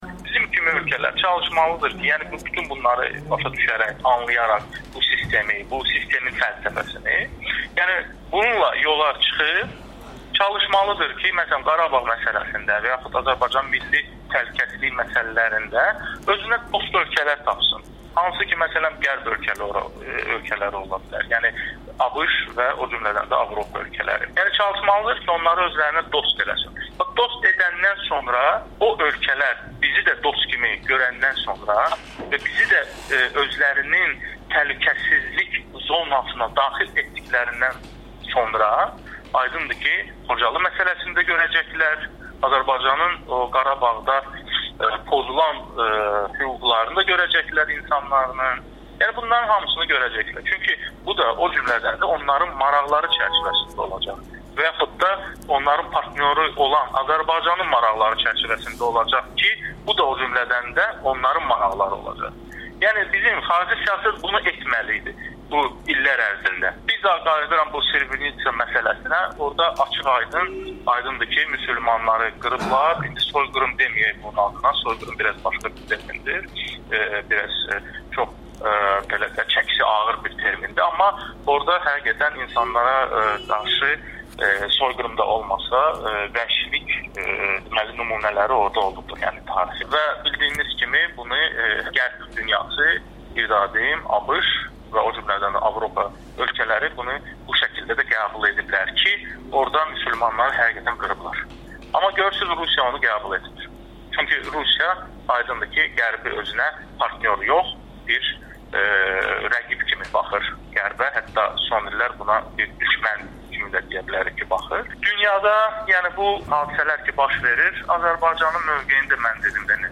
Rusiyanın Srebrenitsa faciəsini qəbul etməməsi Azərbaycanda etirazla qarşılanıb [Audio-müsahibələr]